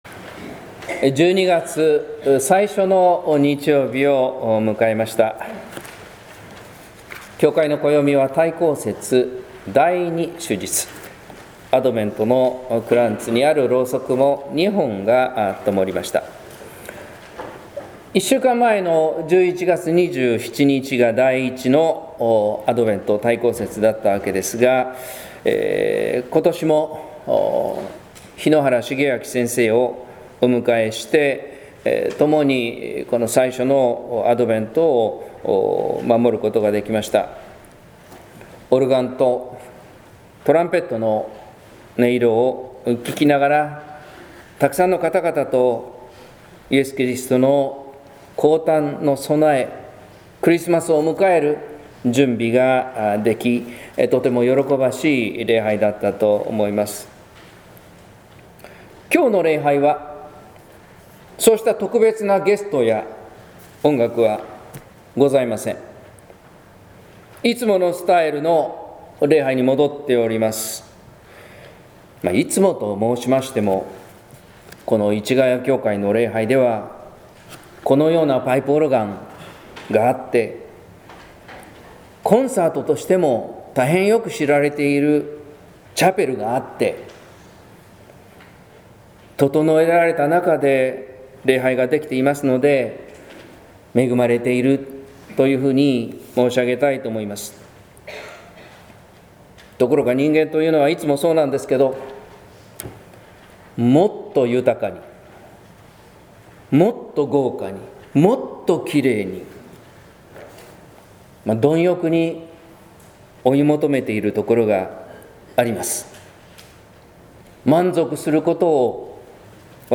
説教「聖霊と悔い改め」（音声版） | 日本福音ルーテル市ヶ谷教会